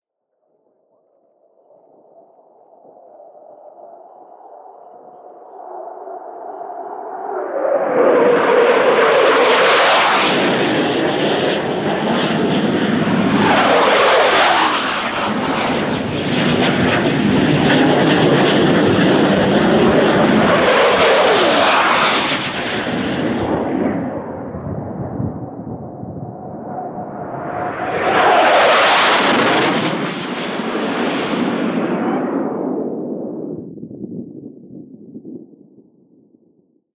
Полет боевого истребителя